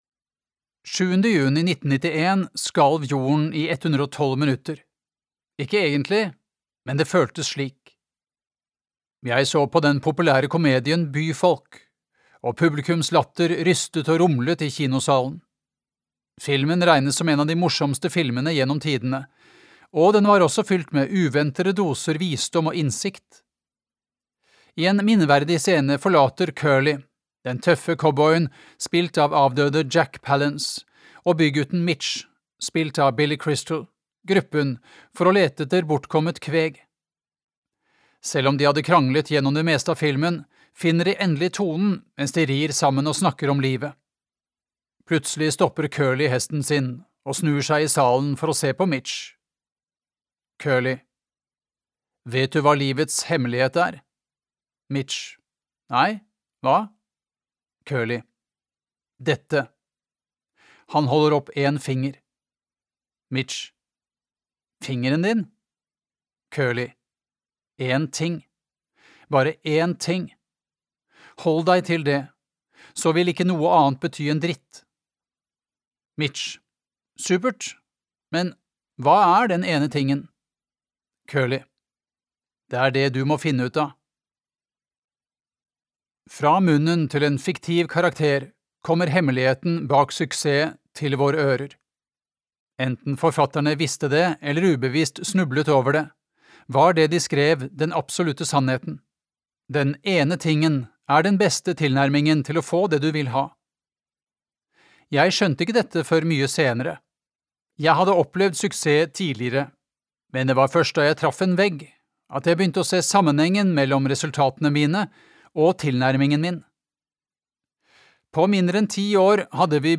Den ene tingen (lydbok) av Gary Keller